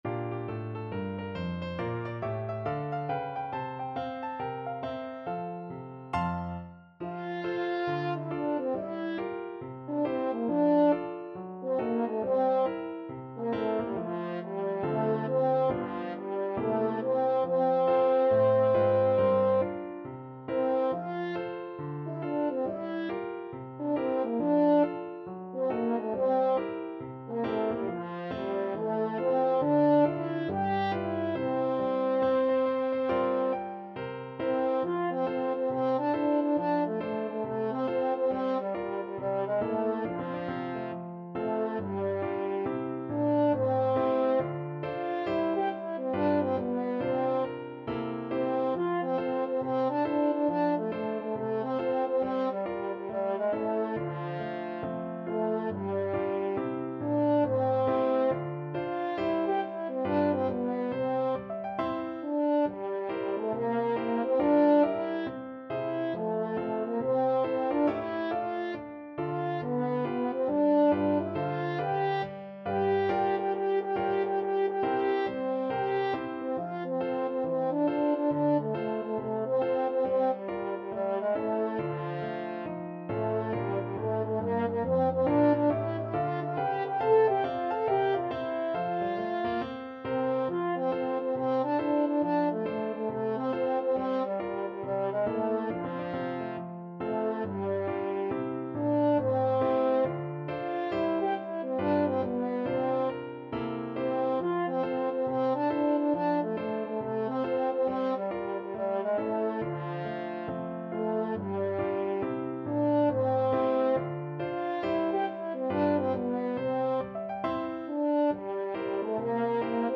French Horn version
With a swing =c.69
4/4 (View more 4/4 Music)
Pop (View more Pop French Horn Music)